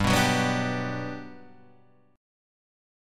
G Major 7th
GM7 chord {3 2 4 4 3 3} chord